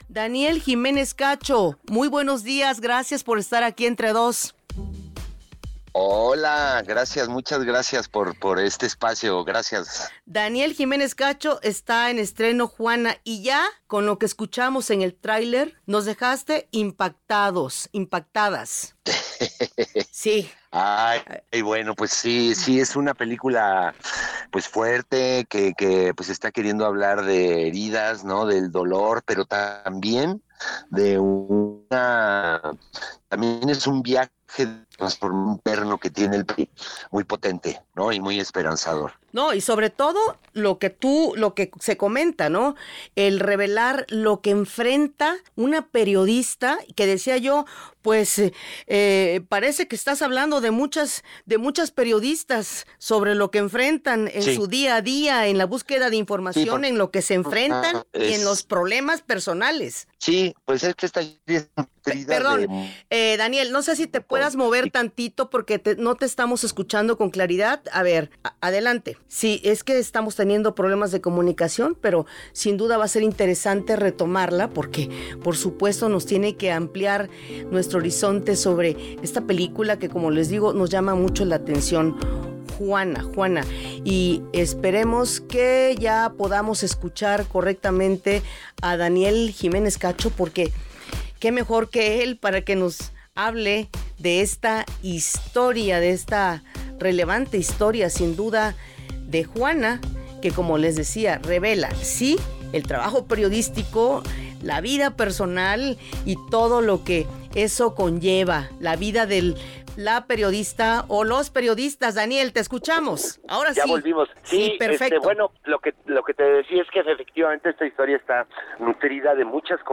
Escucha nuestra conversación con Daniel Giménez Cacho